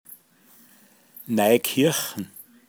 pinzgauer mundart
Noikirchn Neukirchen